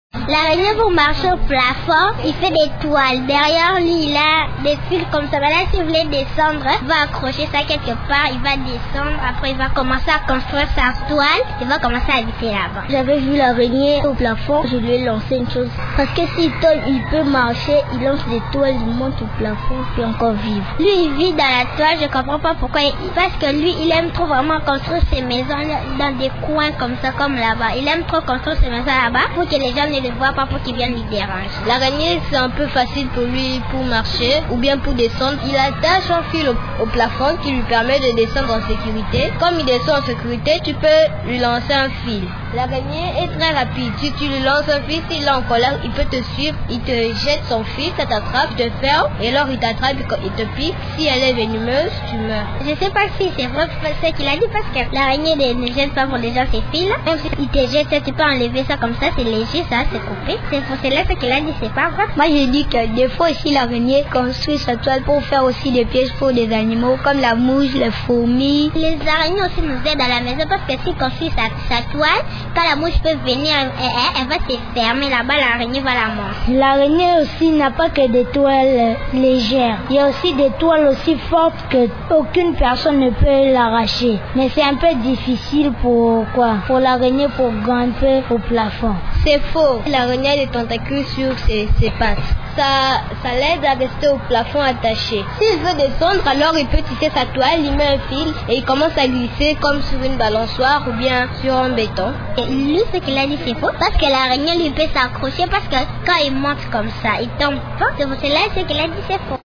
Les enfants parlent des araignées.